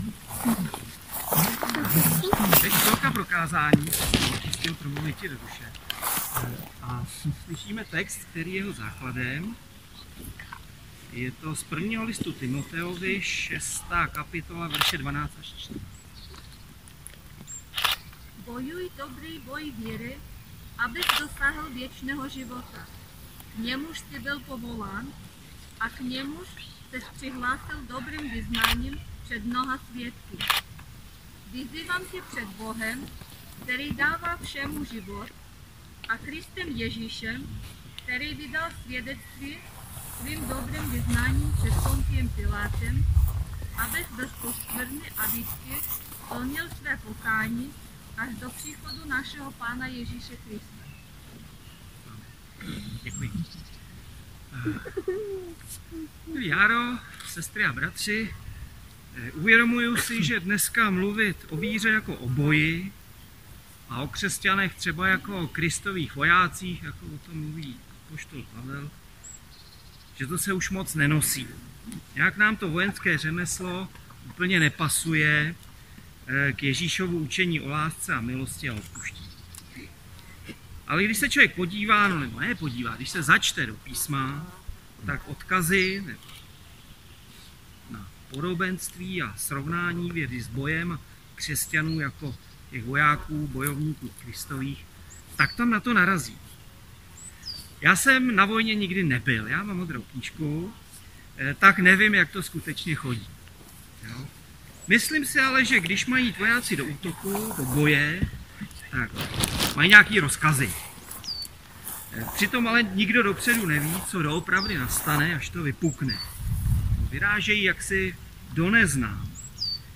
záznam kázání
čtením posloužila sestra
krest-kázání.mp3